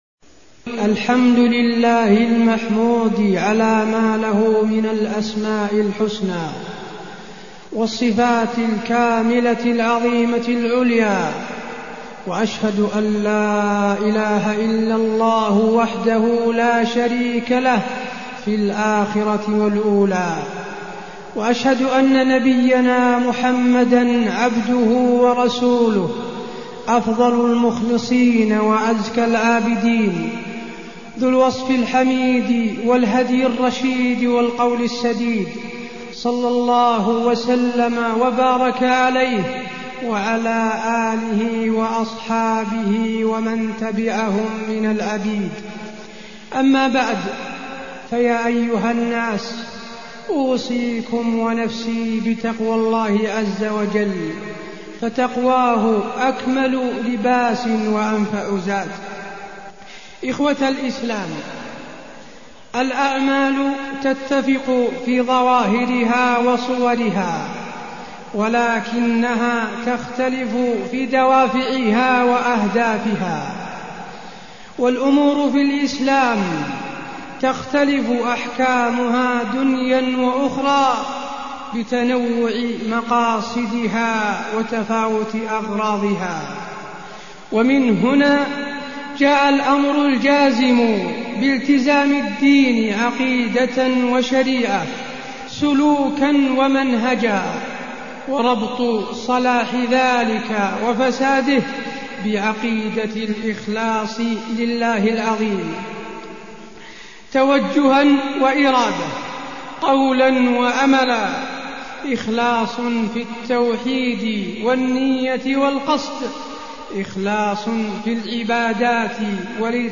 تاريخ النشر ١٨ شعبان ١٤٢٠ هـ المكان: المسجد النبوي الشيخ: فضيلة الشيخ د. حسين بن عبدالعزيز آل الشيخ فضيلة الشيخ د. حسين بن عبدالعزيز آل الشيخ الإخلاص والرياء